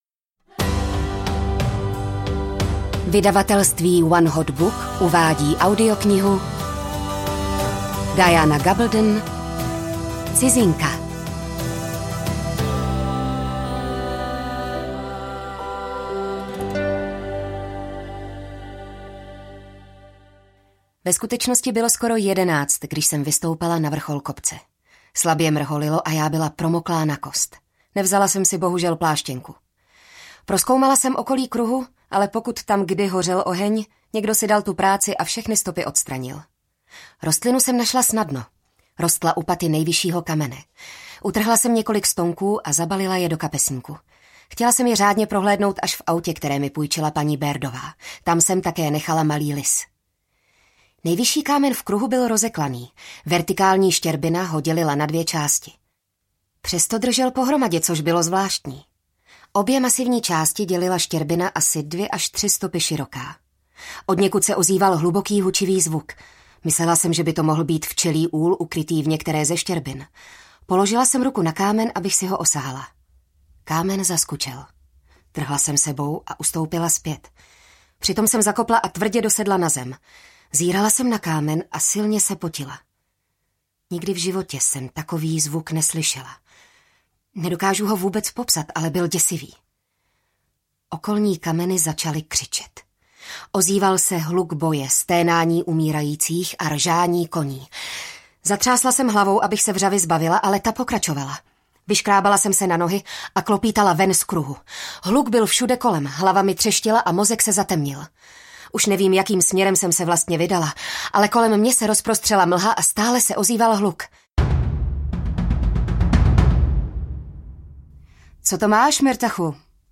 Cizinka audiokniha
Ukázka z knihy